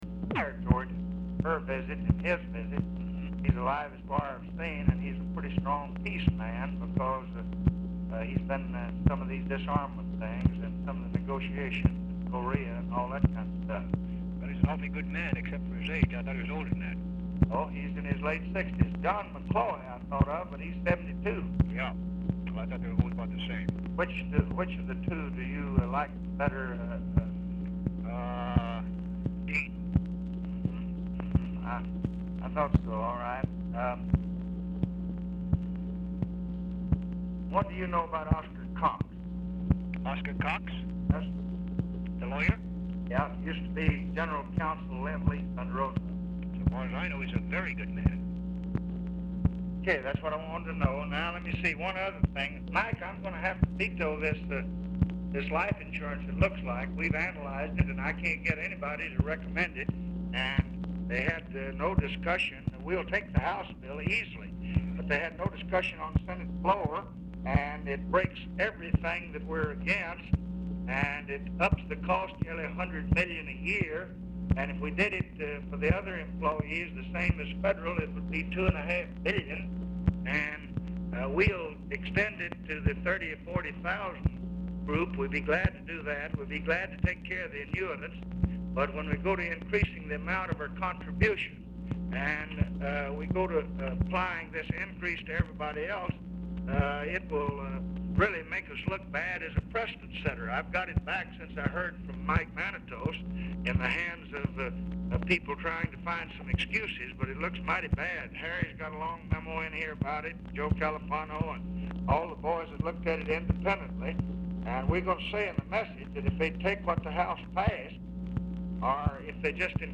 RECORDING STARTS AFTER CONVERSATION HAS BEGUN; POOR SOUND QUALITY
Format Dictation belt
Location Of Speaker 1 Oval Office or unknown location
Specific Item Type Telephone conversation